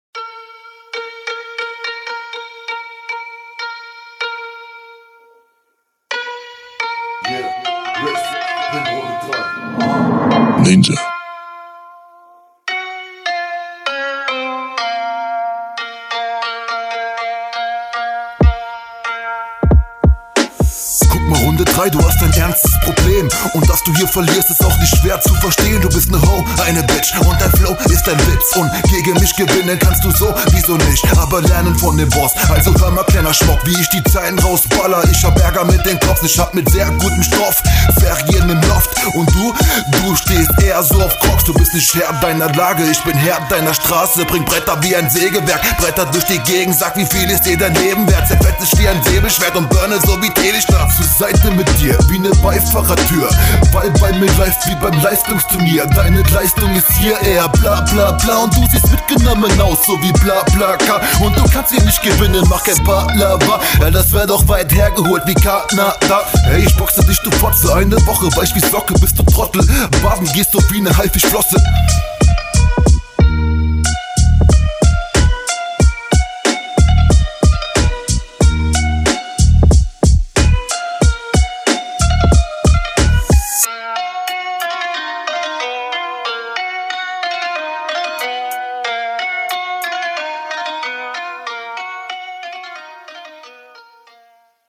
Beat hat was, kenn ich doch irgendwoher.
Beste Runde des Battles, coole reimketten dabei, aber auch ein bisschen hingezweckt.